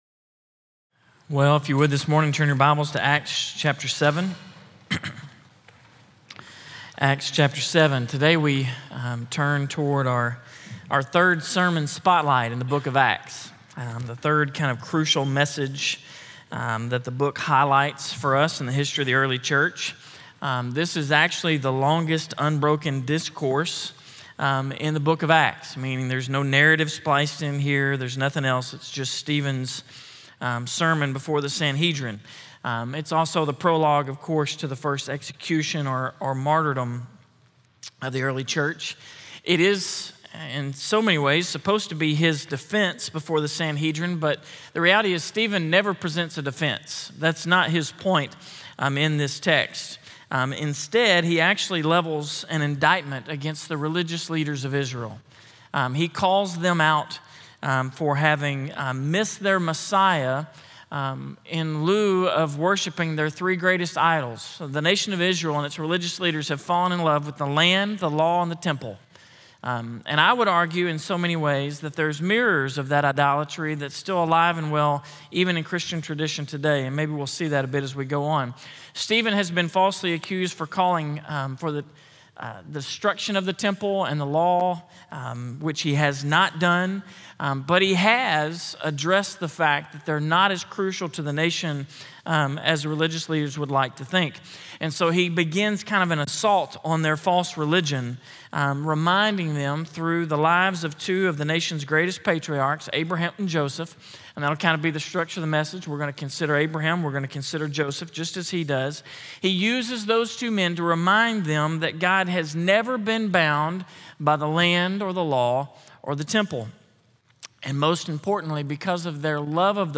Sermon Spotlight, #3 A - First Baptist Church, Russellville, AR